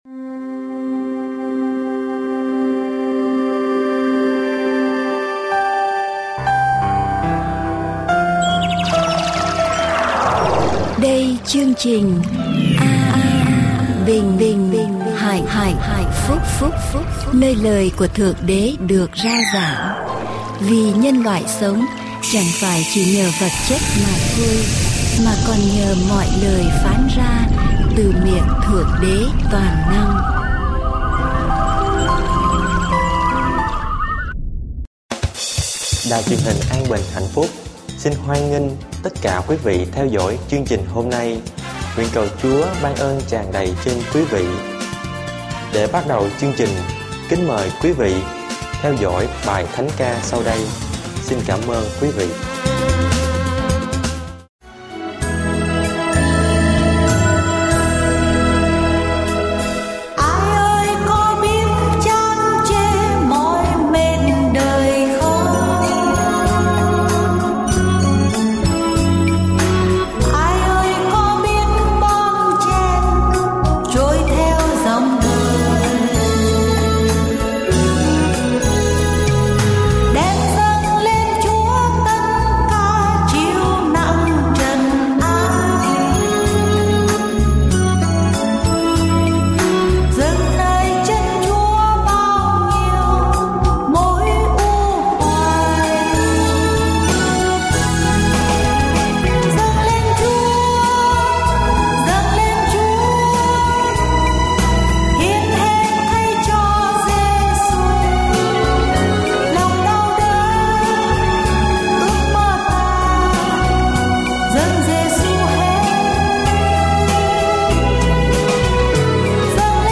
Adventist Vietnamese Sermon